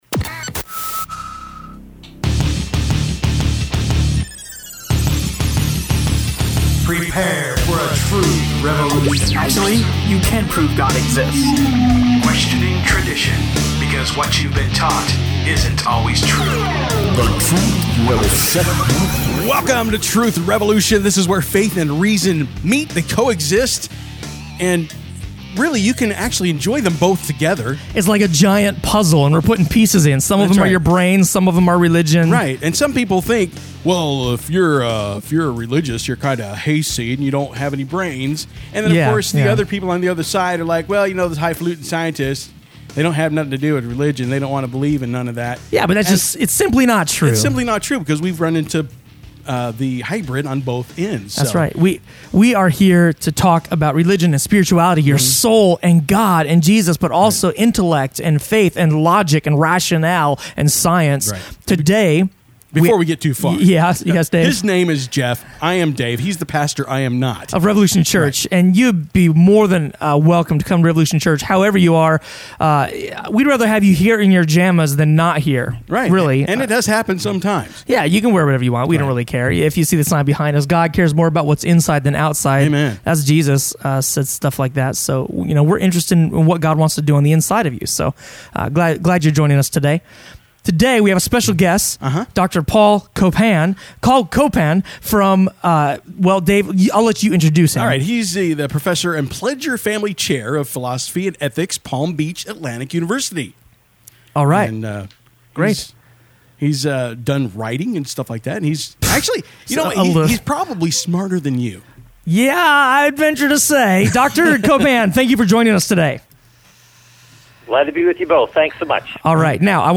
Is God a Moral Monster? – Truth Revolution Radio Show